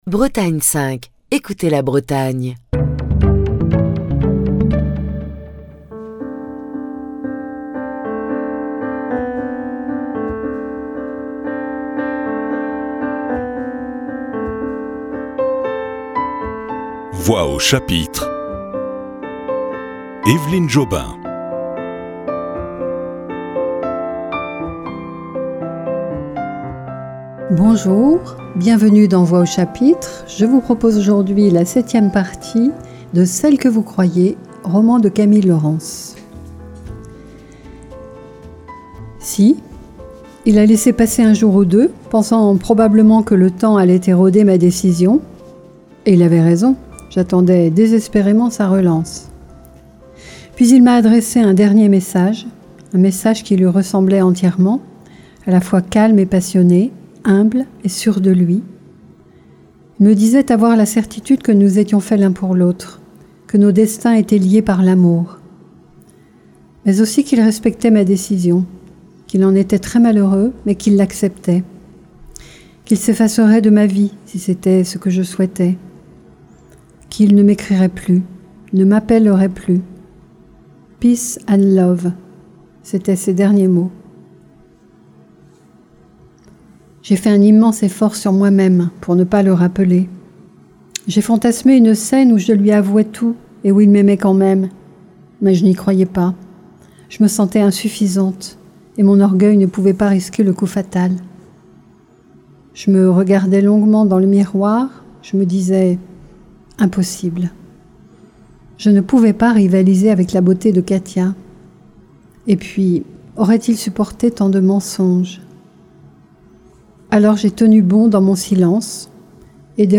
Émission du 16 juin 2024.